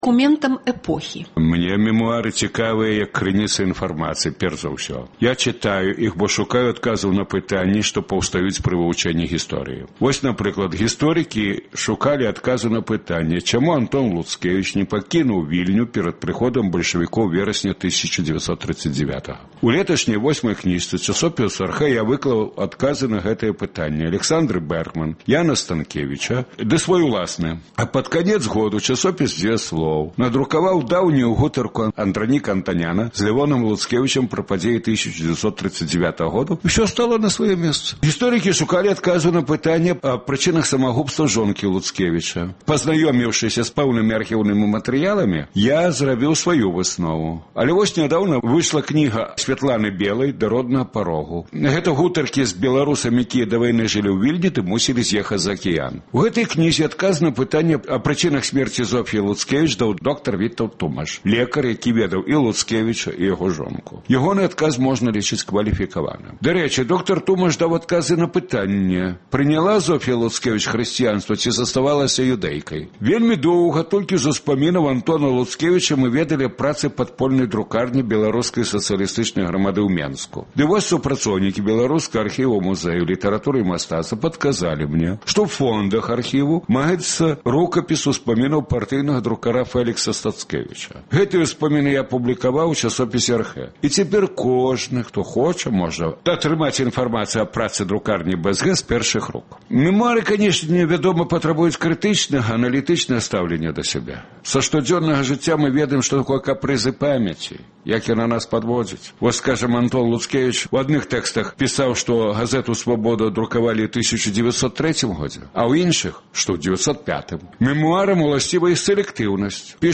Тыднёвы агляд званкоў ад слухачоў Свабоды